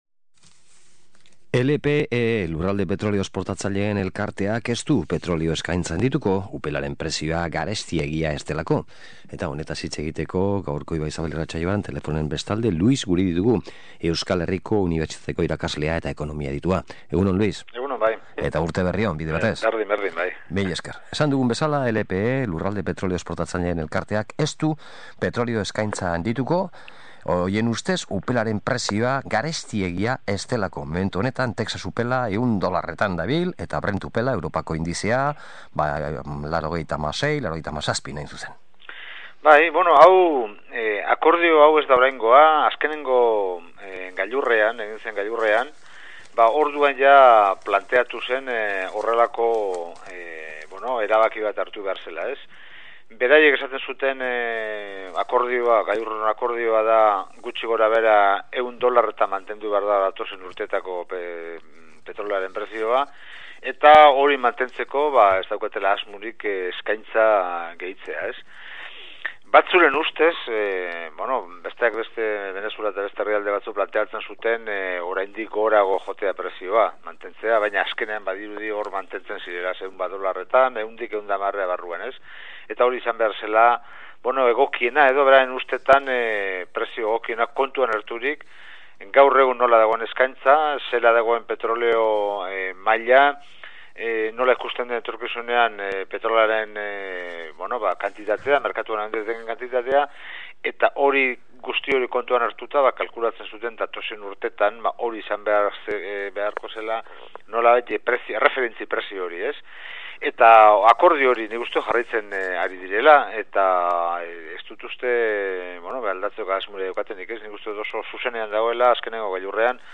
SOLASALDIA: Lindanoa Bizkaian eta zentralak Castejonen